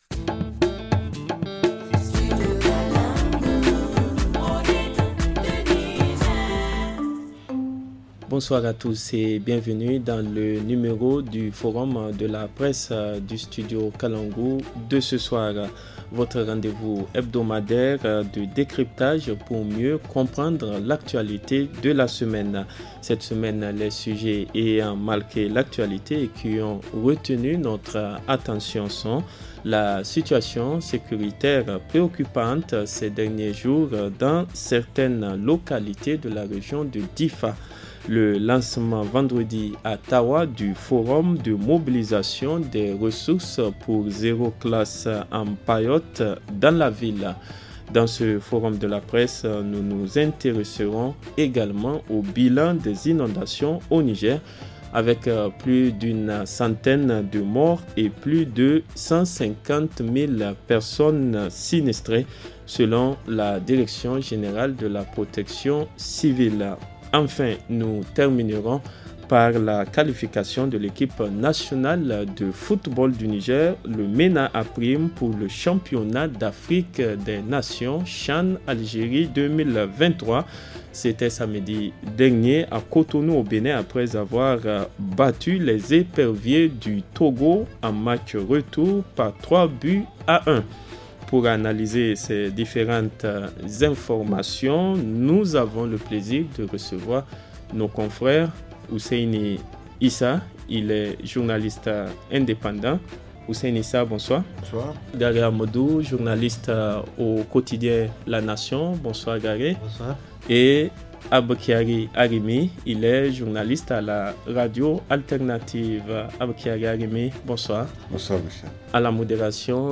journaliste indépendant.